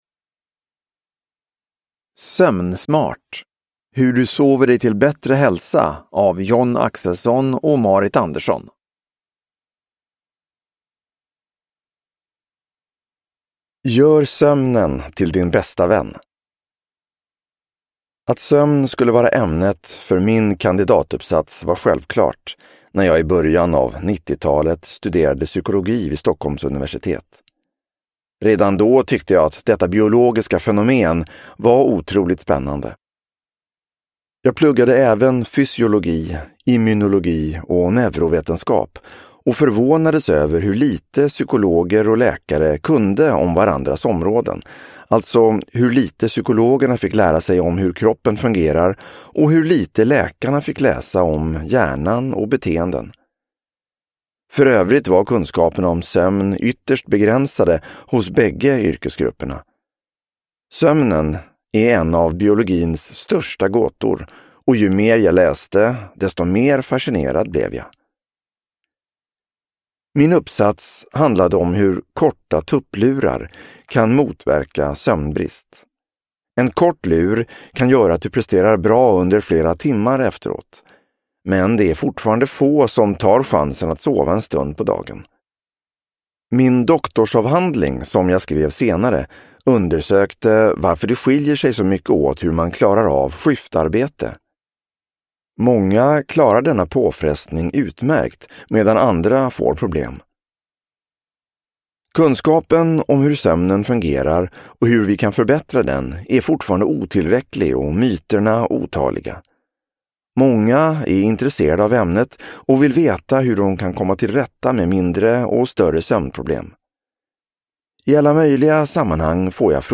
Sömnsmart : hur du sover dig till bättre hälsa – Ljudbok – Laddas ner